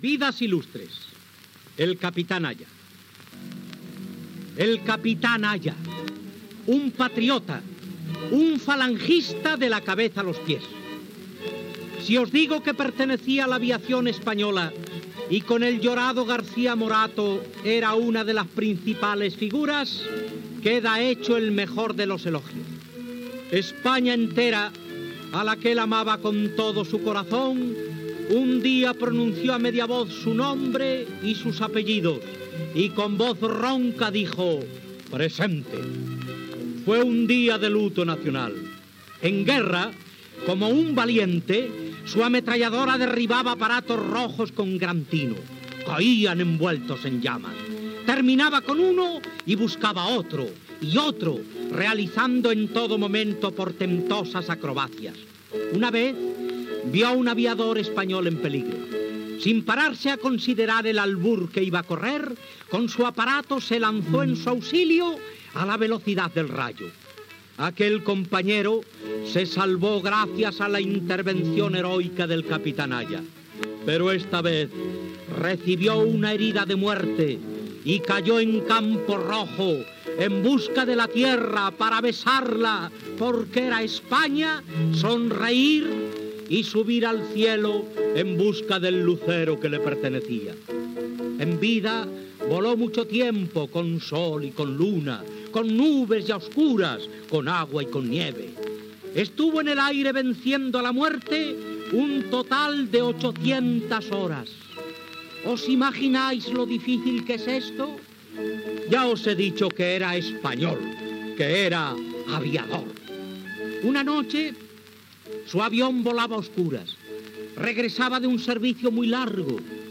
Infantil-juvenil